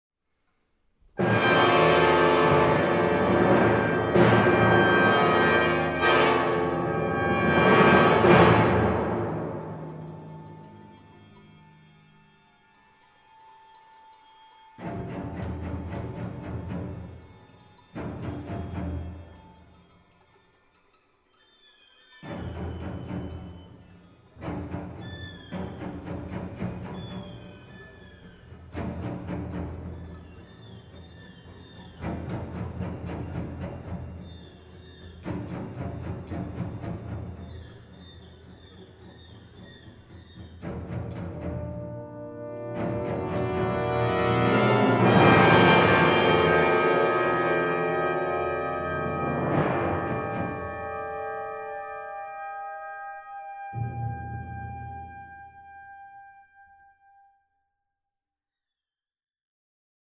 for Brass, Timpani and Strings